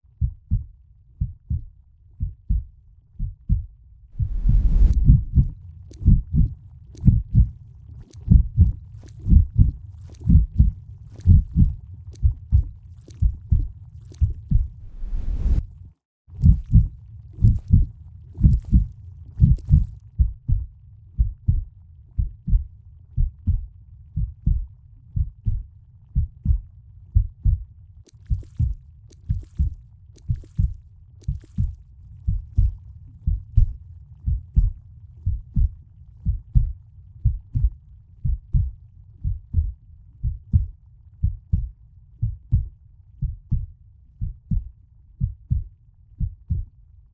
Heart beat.Valve move and muscle work.Sound design_EM